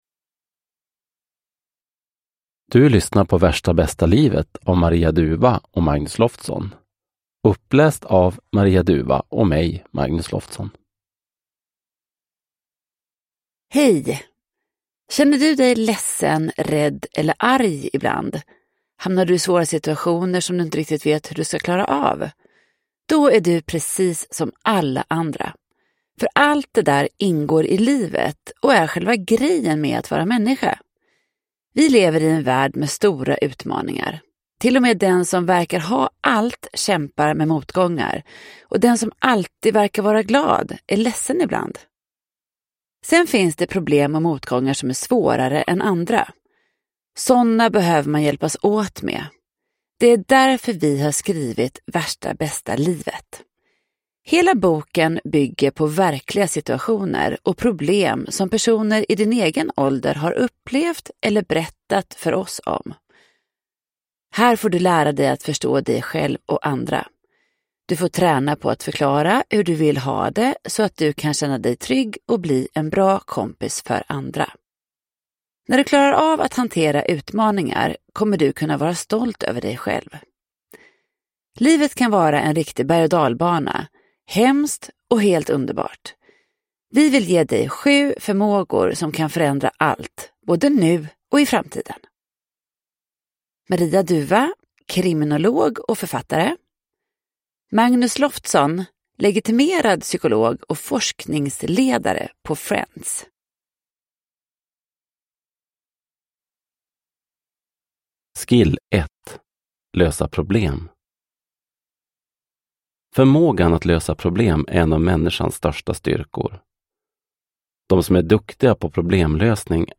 Värsta bästa livet – Ljudbok